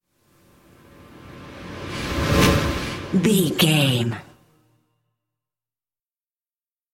Thriller
Aeolian/Minor
strings
drums
cello
violin
percussion